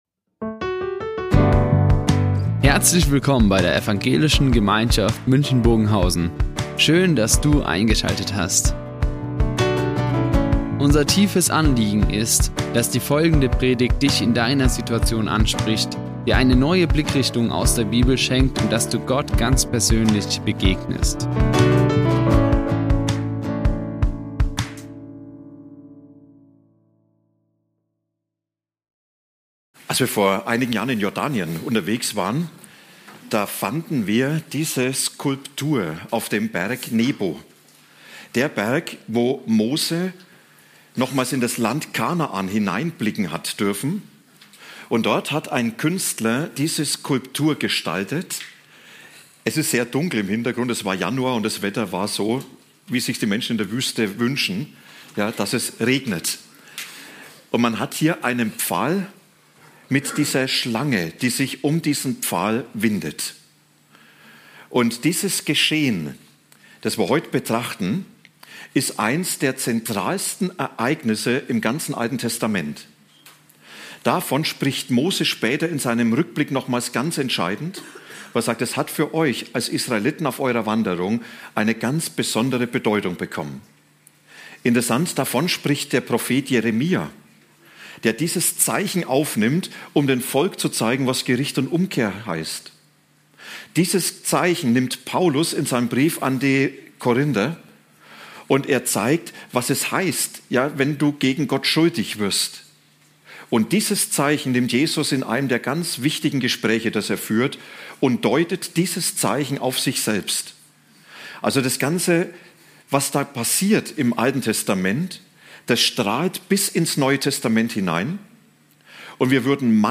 Mose 21,4-9 zum Thema "Das Zeichen der Rettung" Die Aufzeichnung erfolgte im Rahmen eines Livestreams.